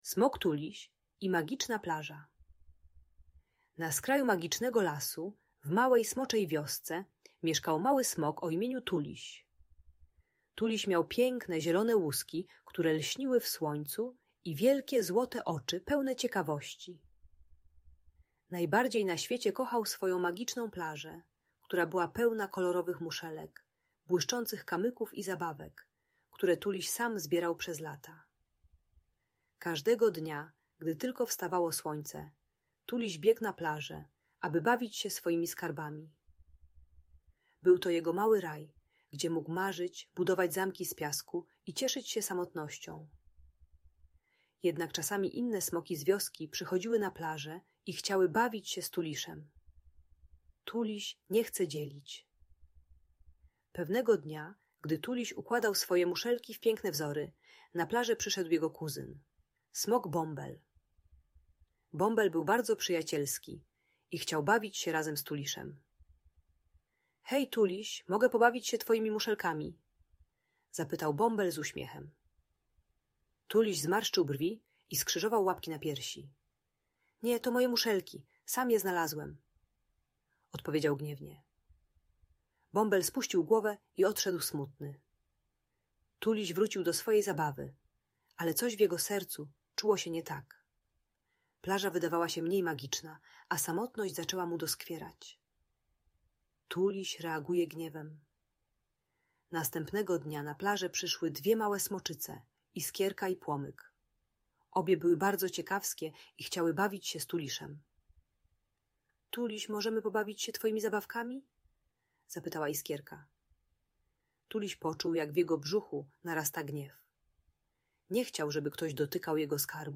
Smok Tuliś i Magiczna Plaża - Audiobajka